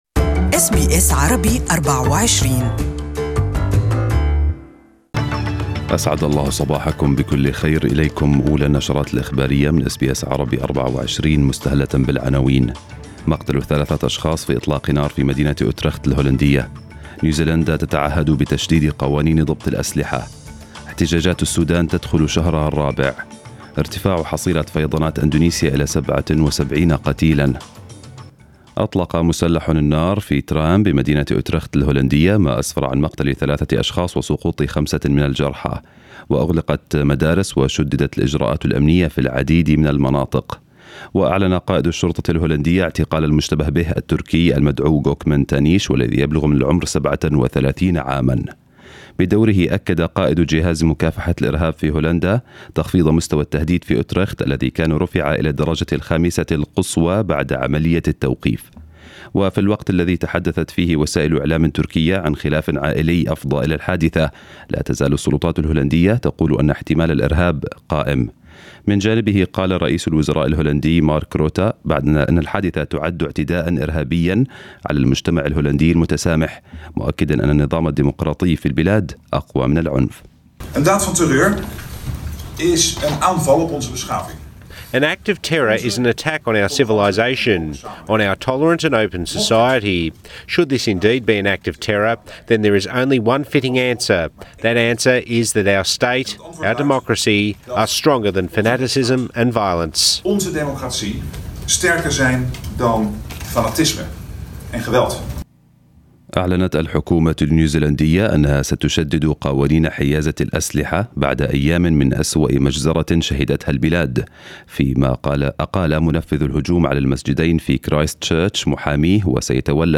News bulletin in Arabic for the day